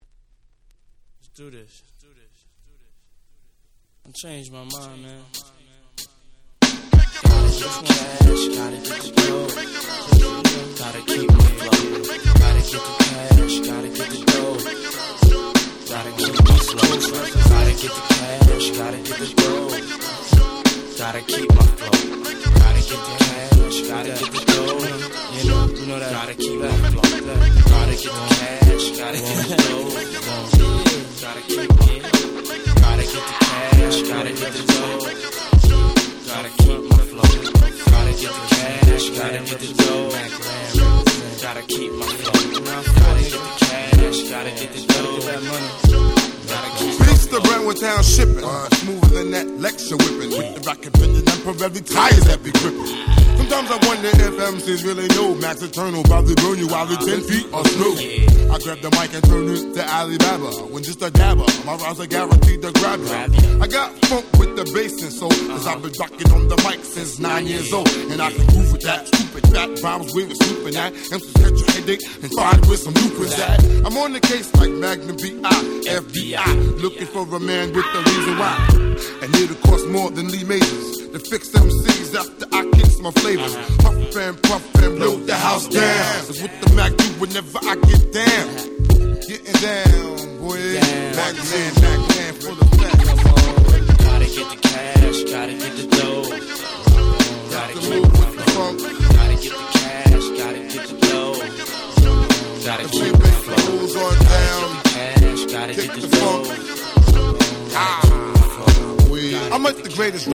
95' Nice Hip Hop !!
JazzyでDope、まさに90's Hip Hop !!
Boom Bap